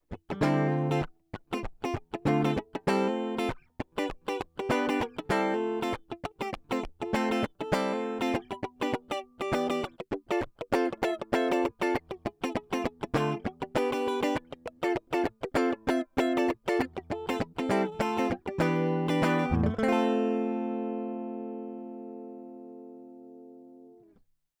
今回録音して頂いた、音源サンプルでは、入力のゲインを上げ気味で、出力で下げるという使い方での録音もして頂きました。
やりすぎると歪んじゃいますけど、歪むギリギリのところというか、若干サチュレーションがかかるところがNeveのよさで、気持ちいいところだったりしますね。